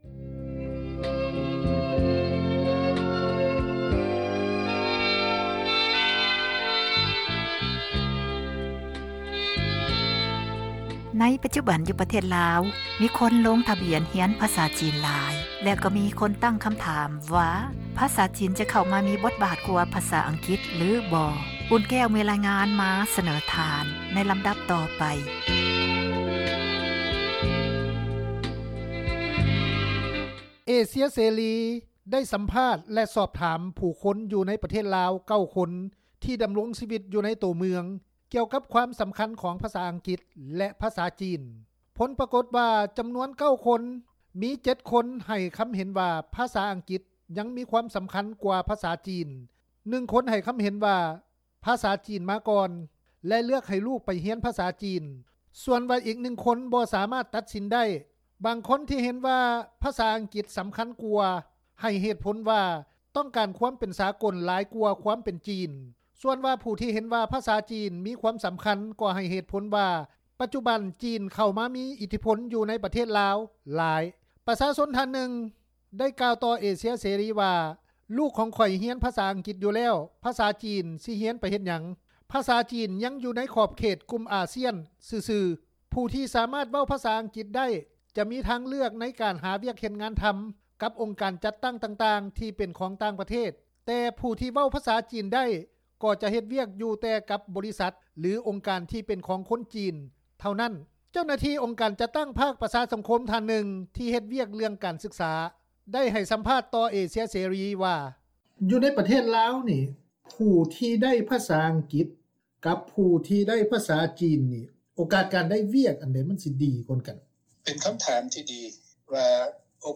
ວິທຍຸ ເອເຊັຽເສຣີ ໄດ້ສັມພາດ ແລະ ສອບຖາມ ຜູ້ຄົນຢູ່ປະເທດໃນລາວ 9 ຄົນ ທີ່ດໍາລົງຊີວິດ ຢູ່ໃນໂຕເມືອງ ກ່ຽວກັບຄວາມສໍາຄັນ ຂອງພາສາອັງກິດ ແລະ ພາສາຈີນ.